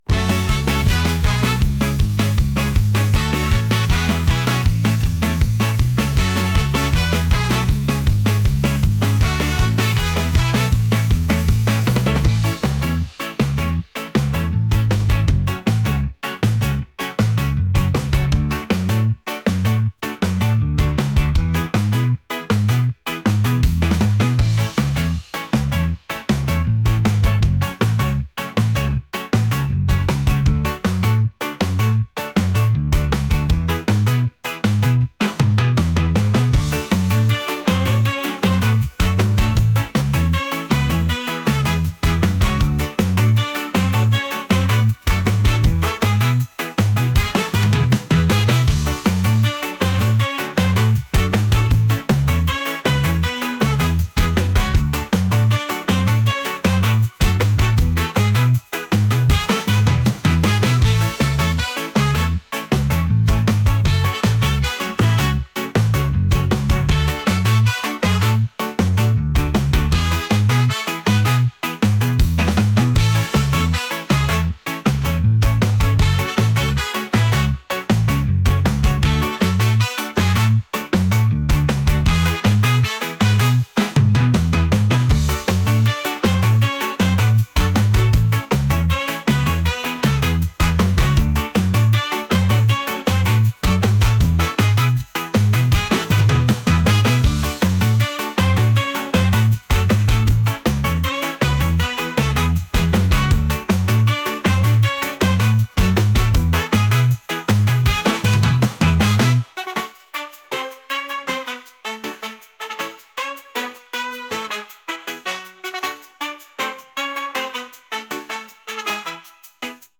reggae | rock | pop